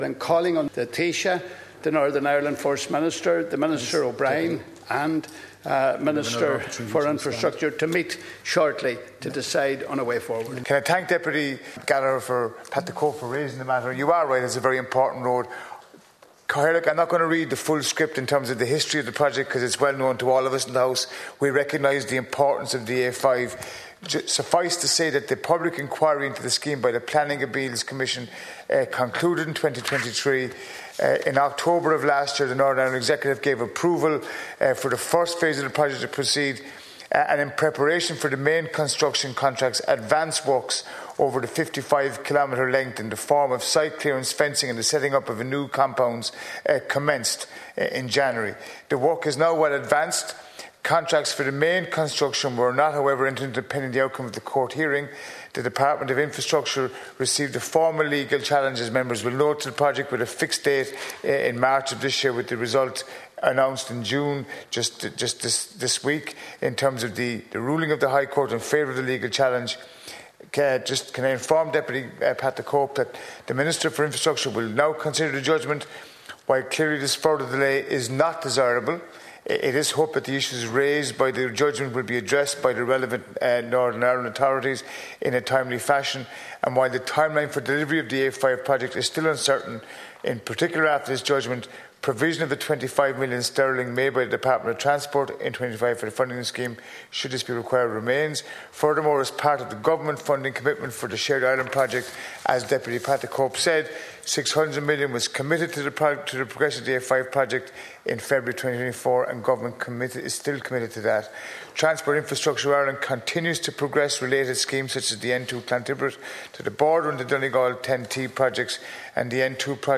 The issue was raised in the Dail last night by Deputy Pat the Cope Gallagher.